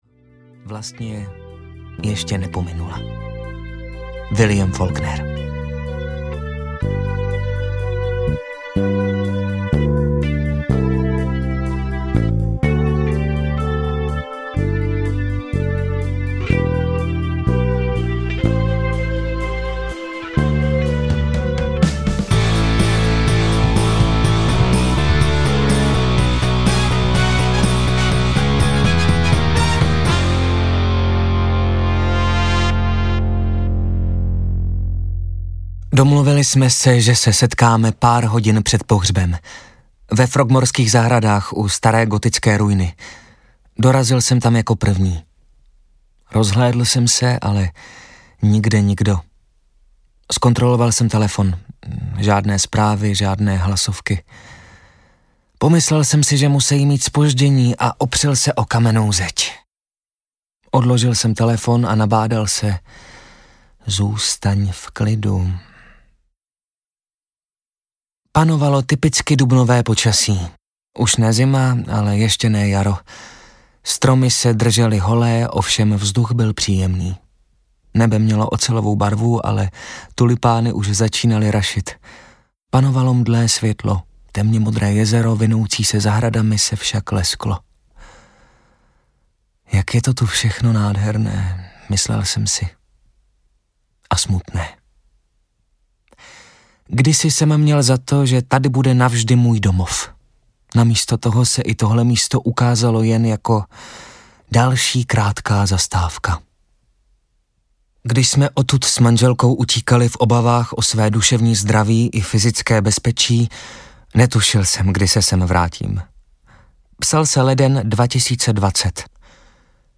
Náhradník audiokniha
Ukázka z knihy